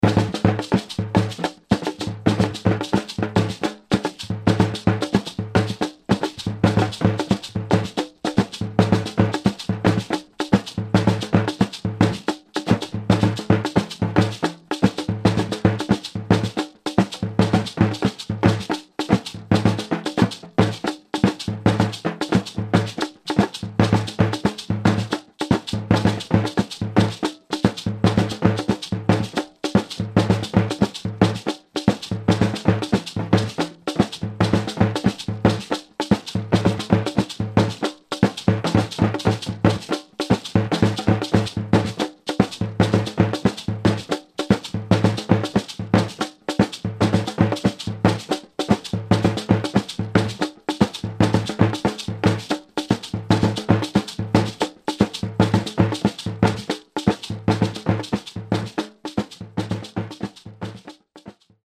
The gingaung pang is a double-skin snare drum used by the Bimoba.
It is struck with one drumstick in one hand and the other hand is used to lightly damp the membrane.
The gingaung pang produces a high note and often plays cross-rhythms with the other percussion instruments for the accompaniment of dances such as the Kuanta and the Tarkutik.